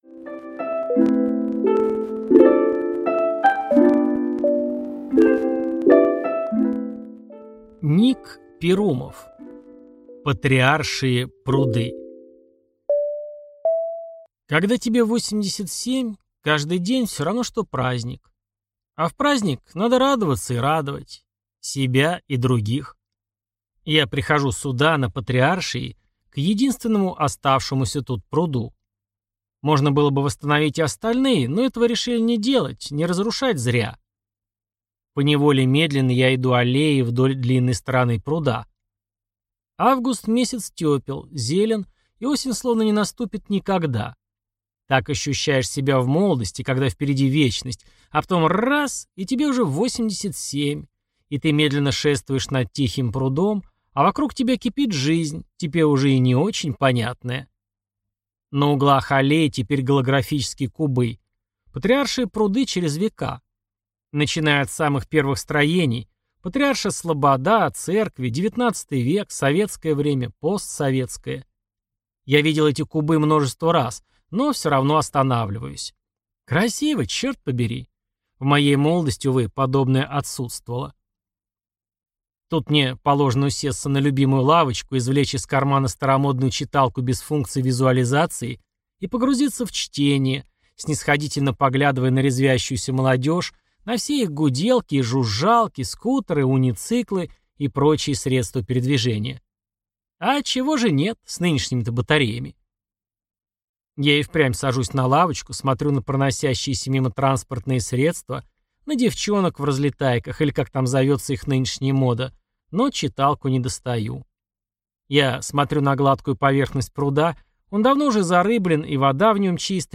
Аудиокнига Патриаршие пруды | Библиотека аудиокниг